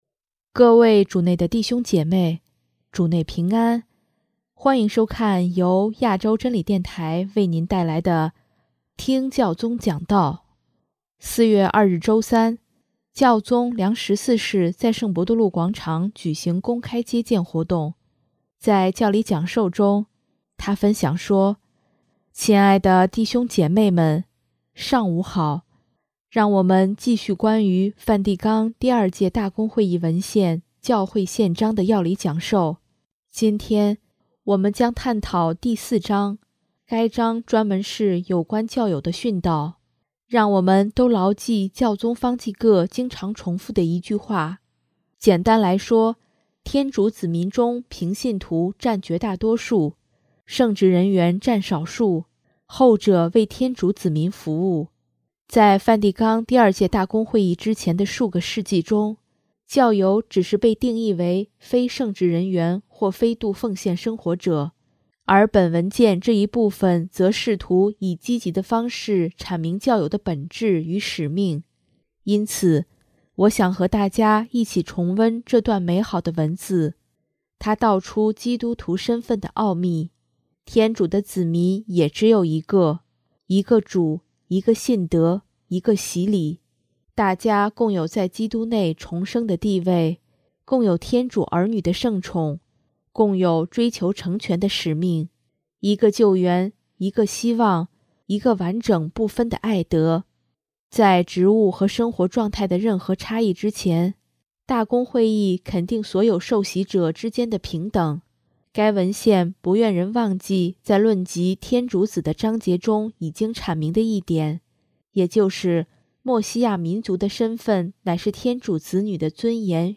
【听教宗讲道】|教友分沾基督的司祭职，蒙召在世上作见证
4月2日周三，教宗良十四世在圣伯多禄广场举行公开接见活动。